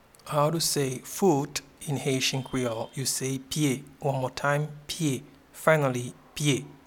Pronunciation and Transcript:
Foot-in-Haitian-Creole-Pye.mp3